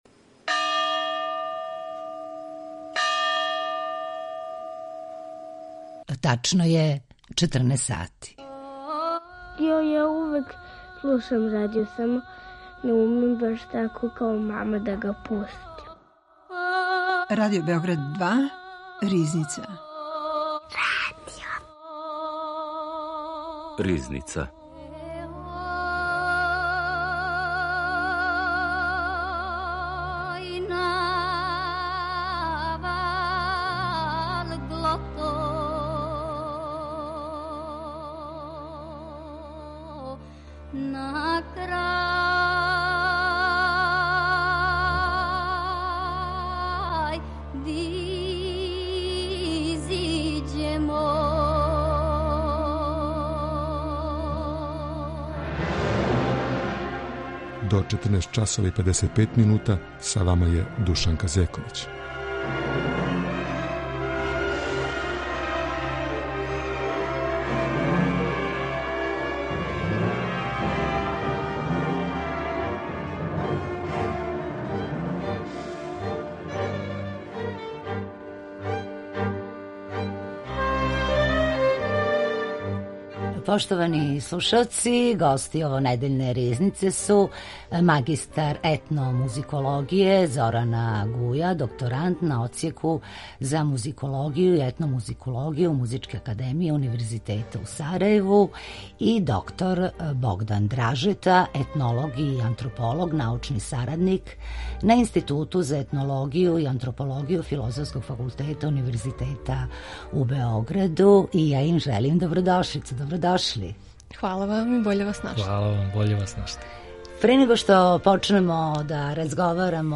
етнолог и антрополог